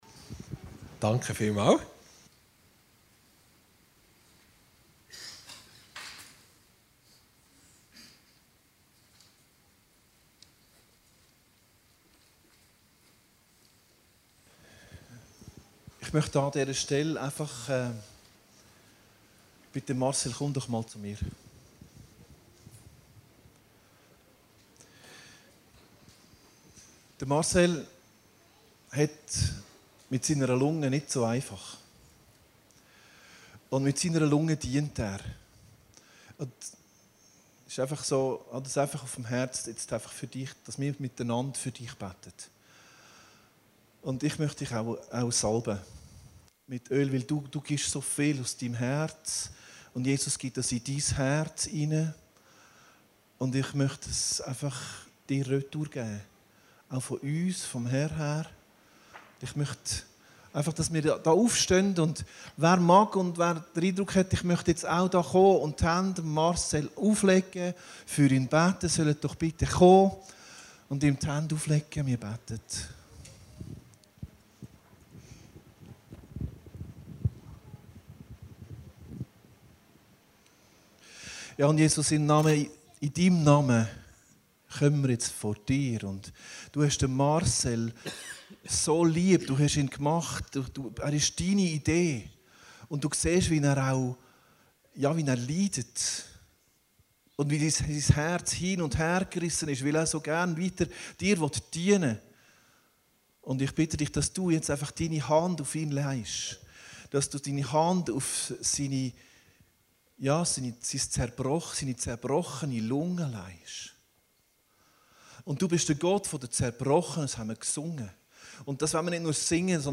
Predigten Heilsarmee Aargau Süd – Leben als Bundespartner Gottes